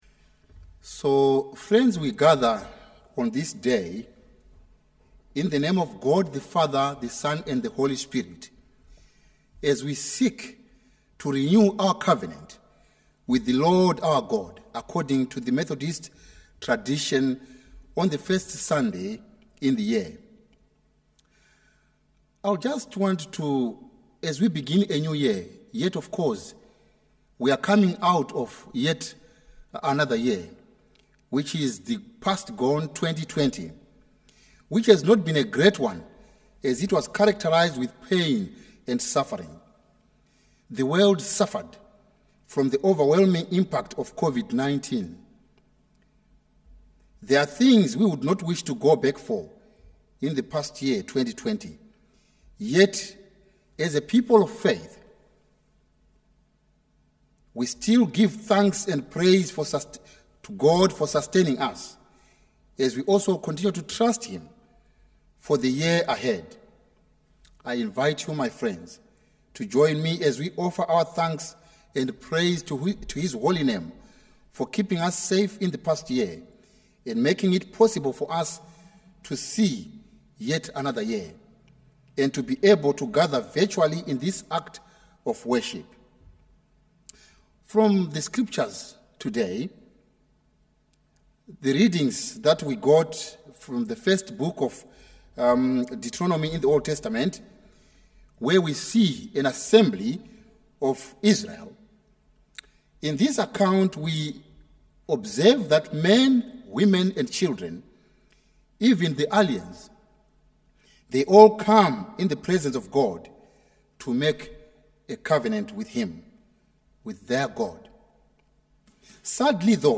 latestsermon.mp3